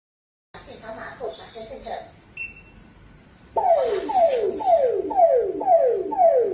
Versucht man mit einem gelben QR-Code in öffentliche Verkehrsmittel einzusteigen, ertönt sofort ein Alarm.